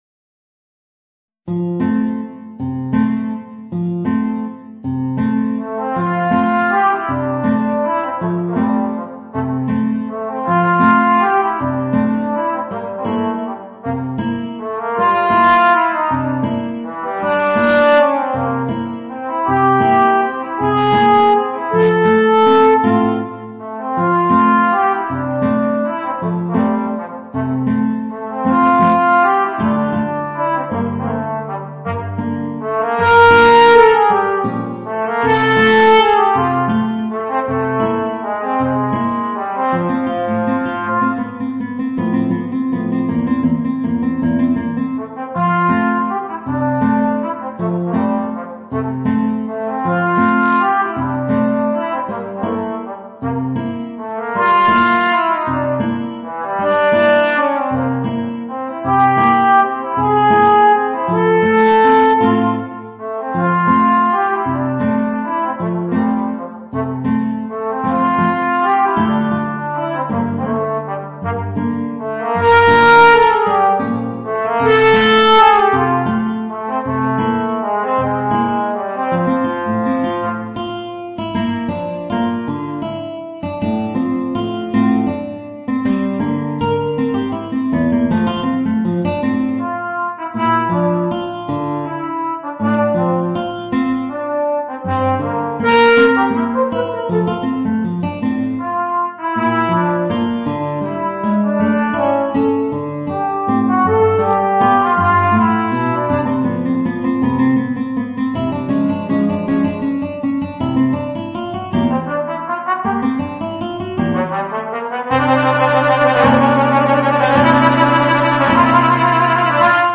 Voicing: Alto Trombone and Guitar